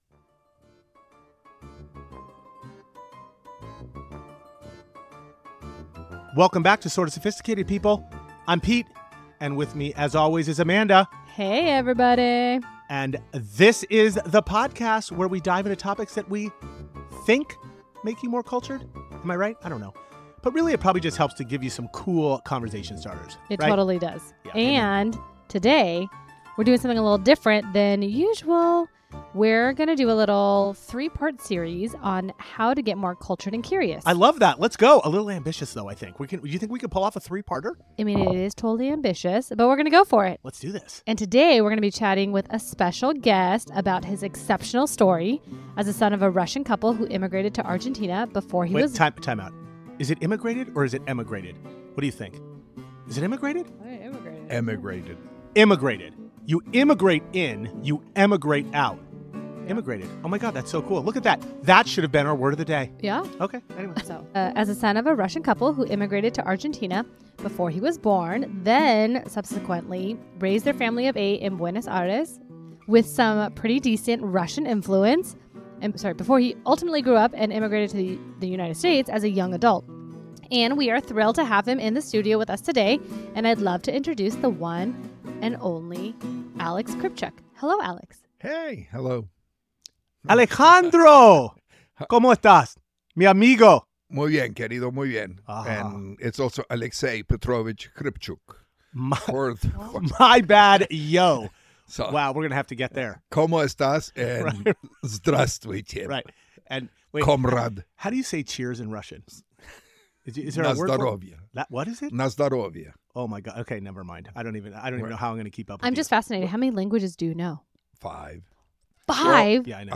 Highlights of this episode include an inspiring conversation with my dear friend